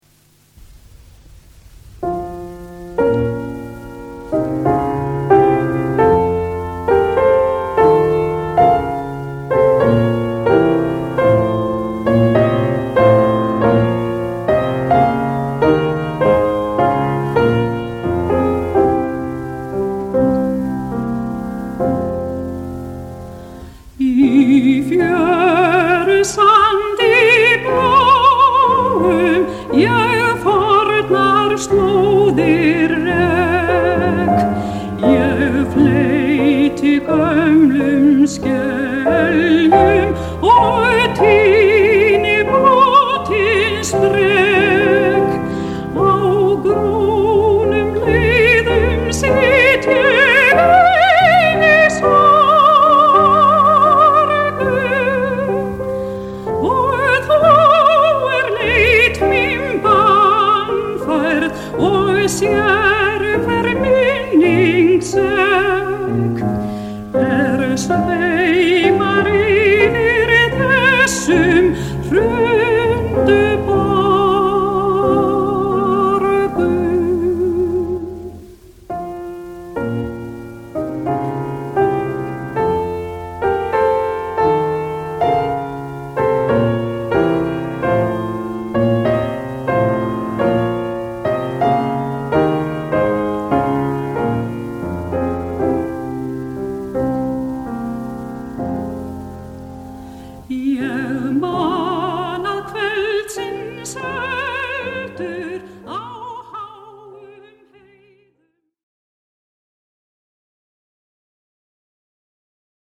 syngur í tóndæminu
leikur á píanóið